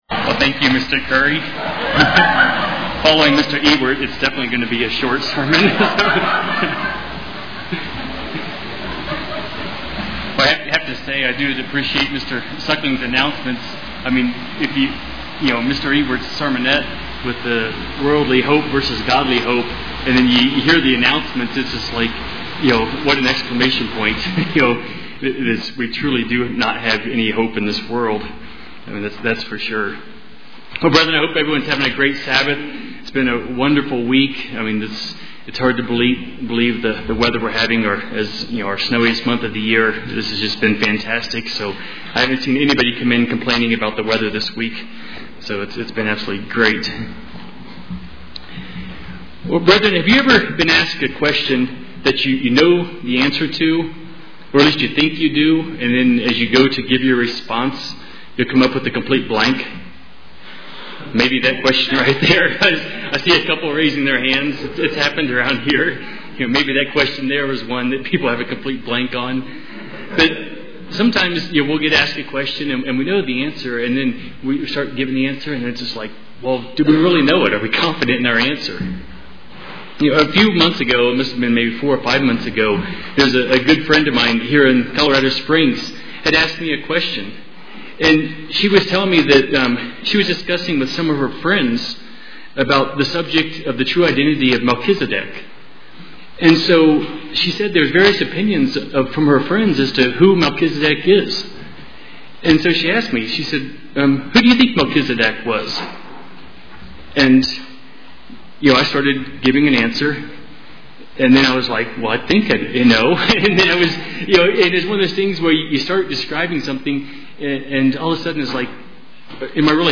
Given in Colorado Springs, CO
Letting the Bible to explain itself, we can determine the real identity of Melchizedek UCG Sermon Studying the bible?